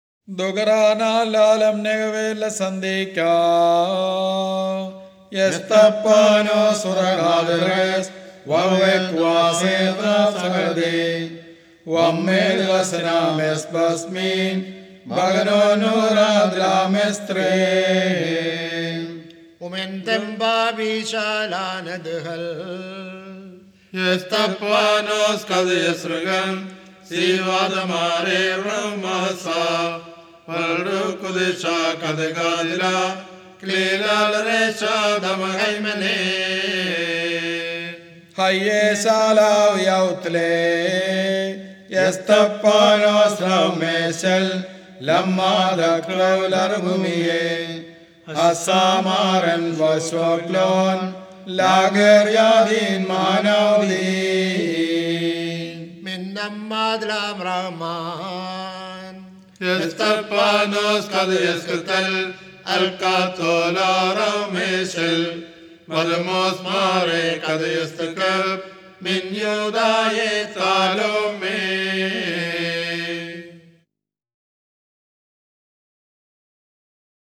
(1m:14s)   Liturgy of the Hours 4e